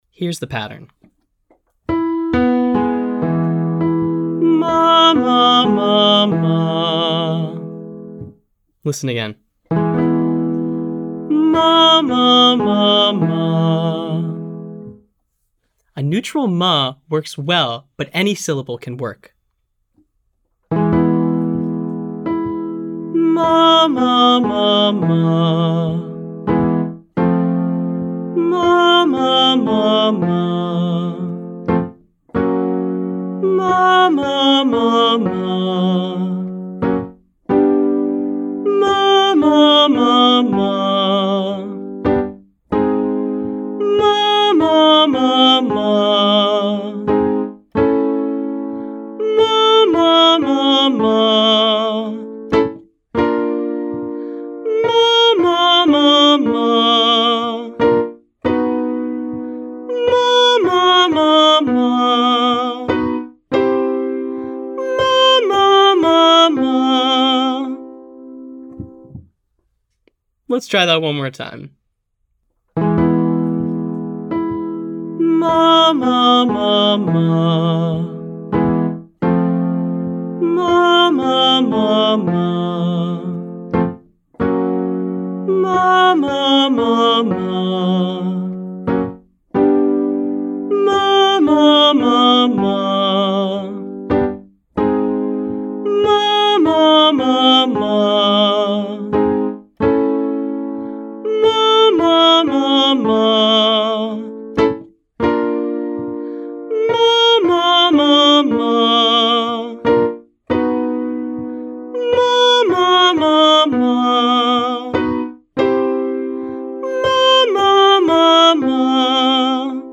This time, we’ll start high and end low.
Prioritize singing what you hear on the piano, even if it means your voice “cracks” or sounds weaker on some pitches.